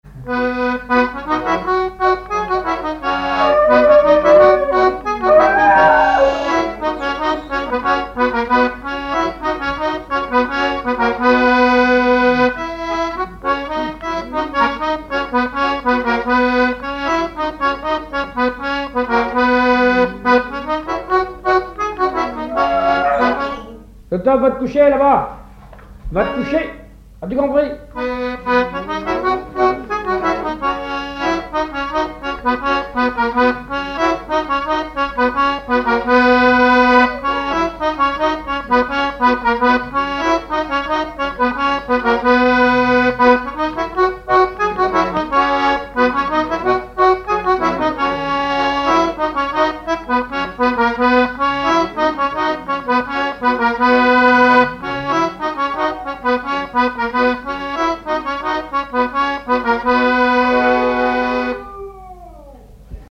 Saint-Hilaire-de-Riez
danse : sicilienne
accordéon diatonique
Pièce musicale inédite